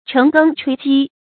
惩羹吹齑 chéng gēng chuī jī
惩羹吹齑发音